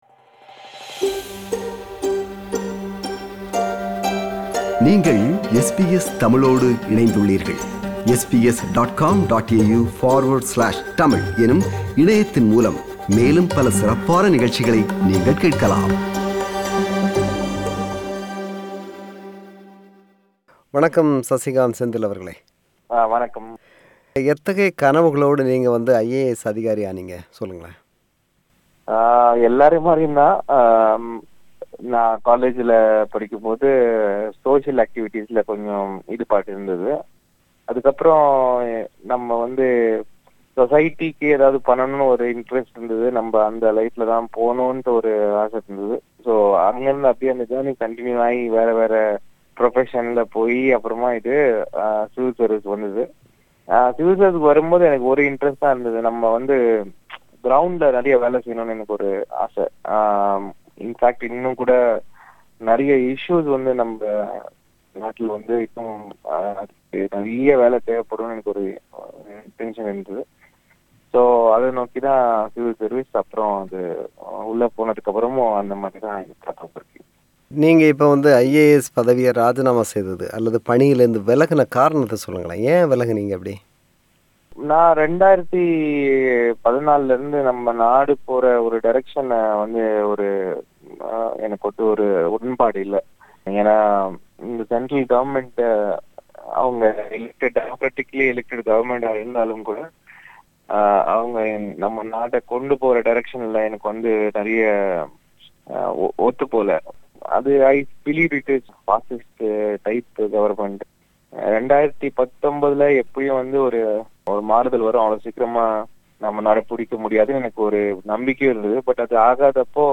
IAS பணியிலிருந்து விலகிய காரணம், தேச துரோகி என்று அவர் மீது முன்வைக்கப்படும் விமர்சனம், அவரின் எதிர்கால திட்டம் என்று பல்வேறு அம்சங்கள் குறித்து மனம்திறந்து பேசுகிறார்.